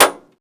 Index of /server/sound/vj_impact_metal/bullet_metal
metalsolid5.wav